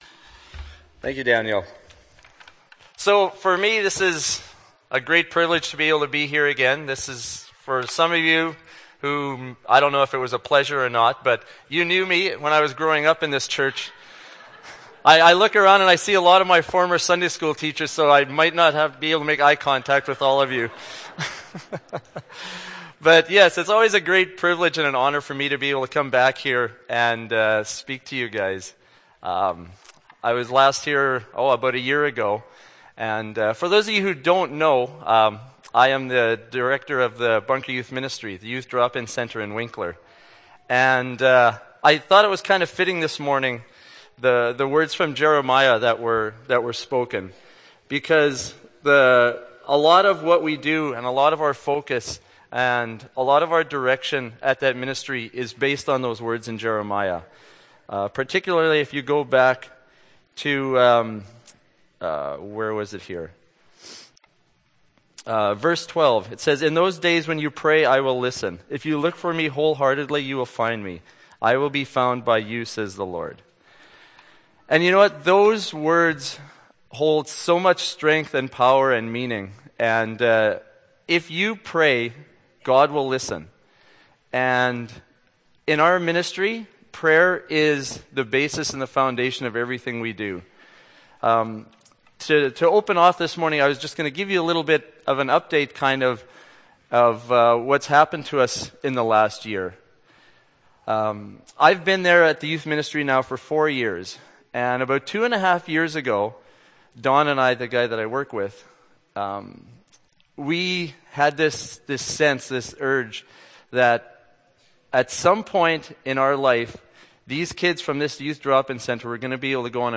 Nov. 27, 2011 – Sermon